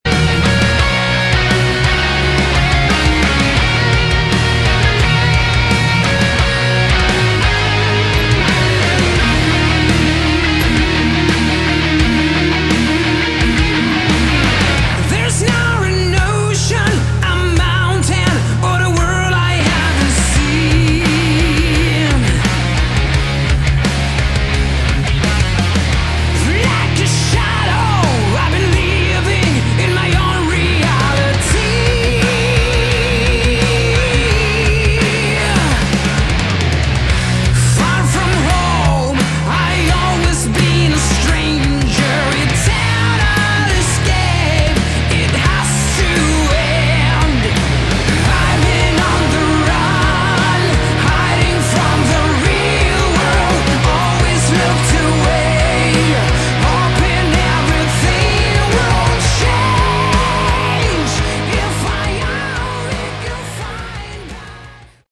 Category: Melodic Metal
Guitars, Bass & Keyboards
Drums